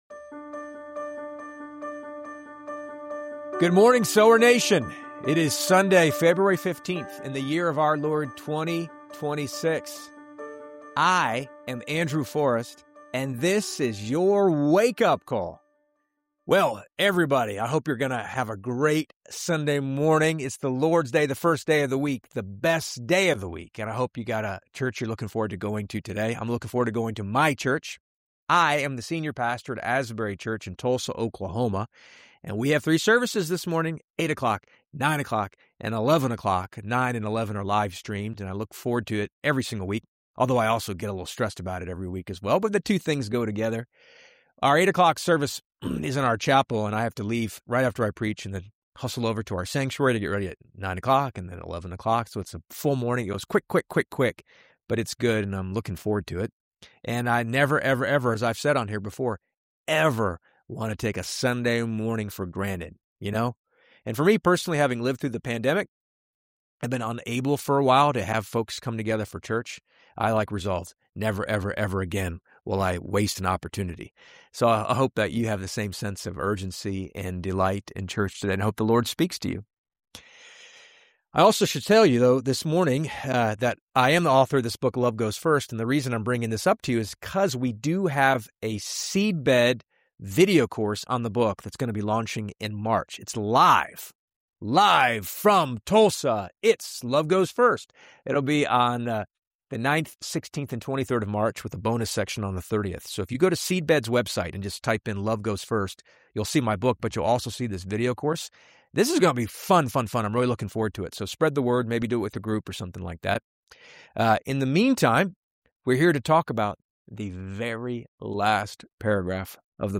CHAPTERS: [00:00:10] Sunday Wake-Up Call and Church Excitement [00:01:21] Announcing "Love Goes First" Video Course Launch [00:02:37] Reading the Final Paragraph of Exodus [00:03:38] The Glory of God Fills